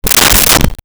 Door Close 02
Door Close 02.wav